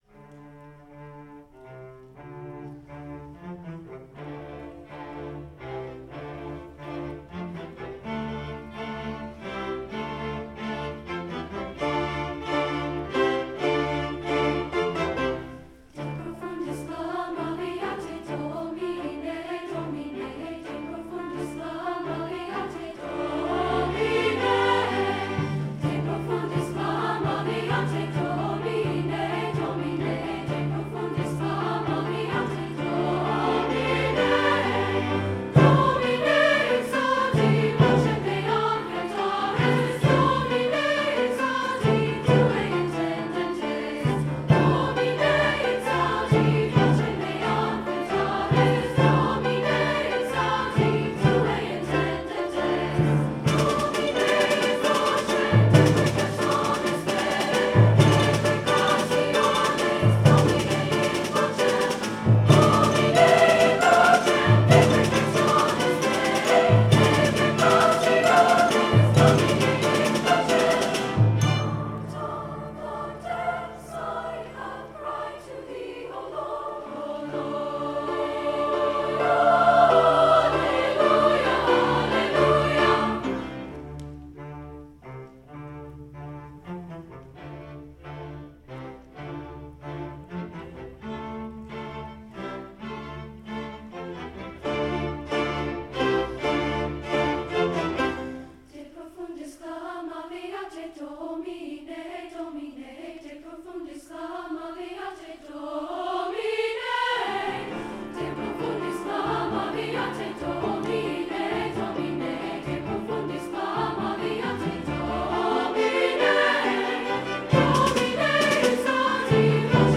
lawson gould choral